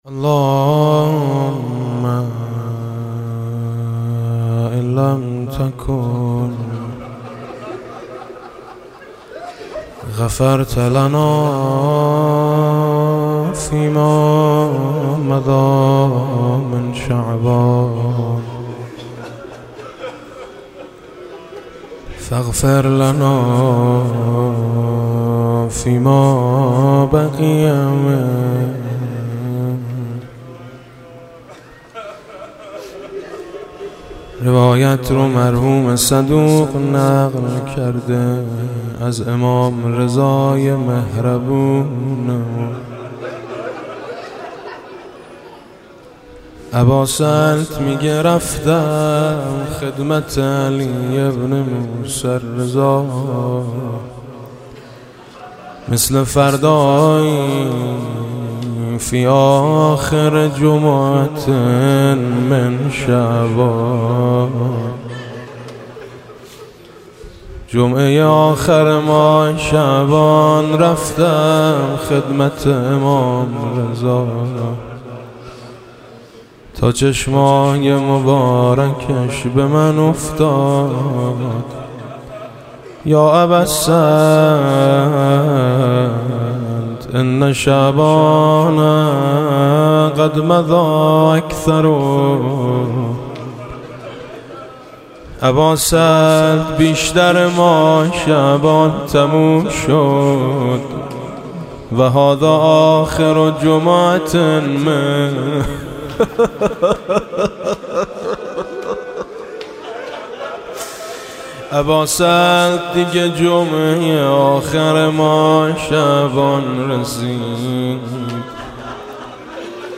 دعای روزهای آخر ماه شعبان با صدای حاج میثم مطیعی دریافت